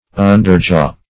underjaw - definition of underjaw - synonyms, pronunciation, spelling from Free Dictionary Search Result for " underjaw" : The Collaborative International Dictionary of English v.0.48: Underjaw \Un"der*jaw`\, n. The lower jaw.